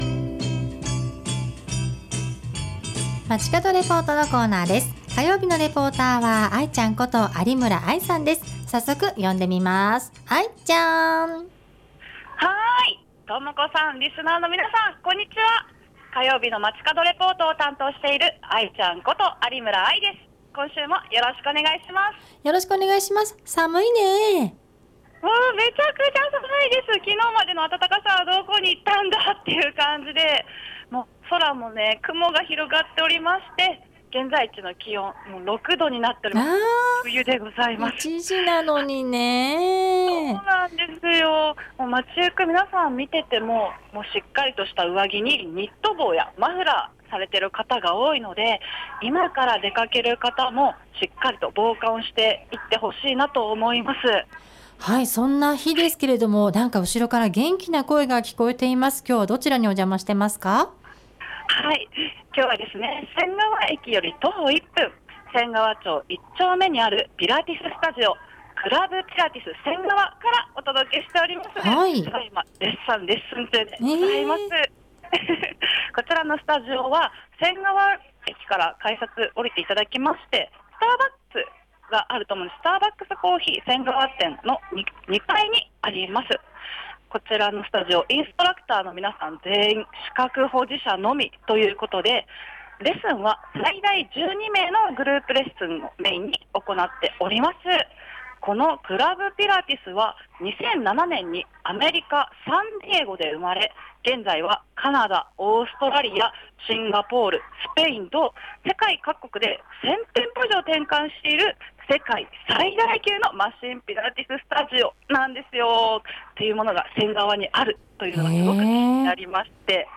今週は仙川にあるマシンピラティススタジオ「CLUB PILATES 仙川」からお届けしました！